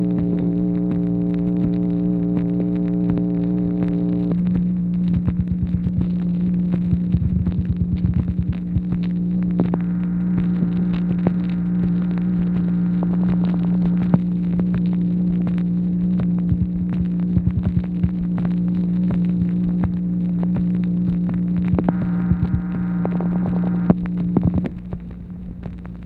MACHINE NOISE, June 18, 1964
Secret White House Tapes | Lyndon B. Johnson Presidency